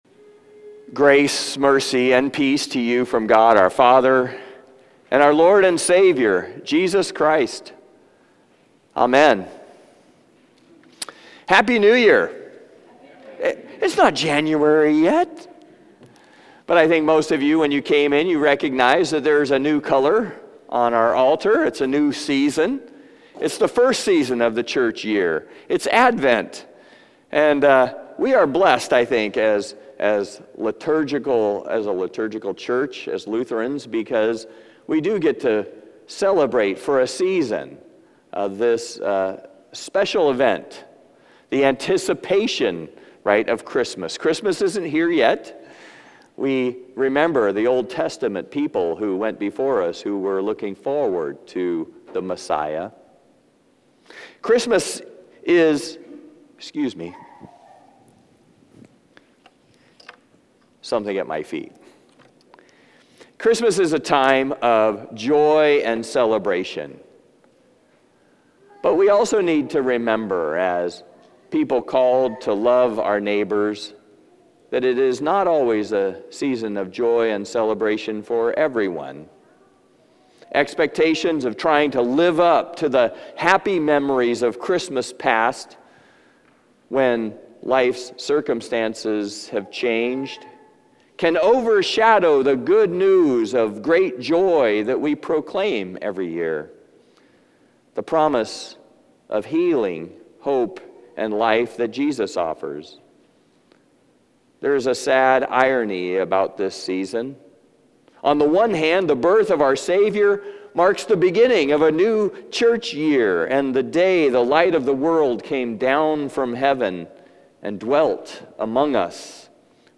John 1:1-5 Service Type: Traditional and Blended « Life Together